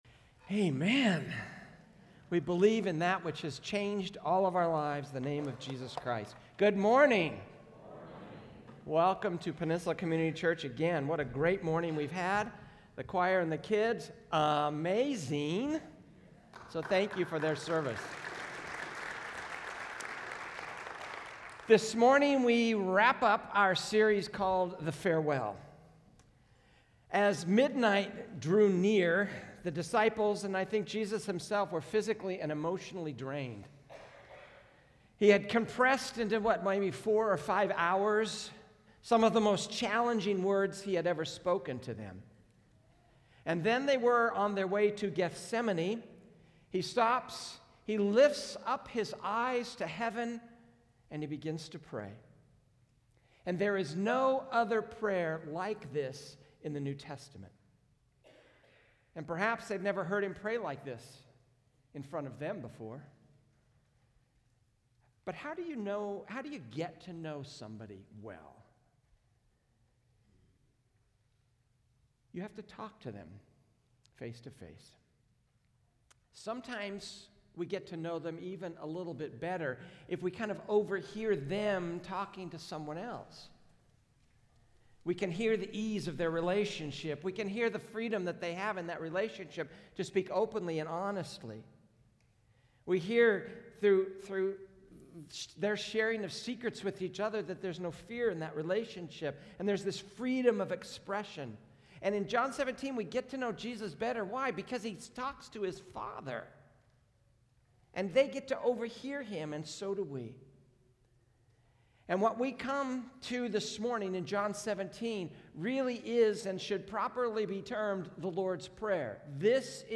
A message from the series "The Farewell."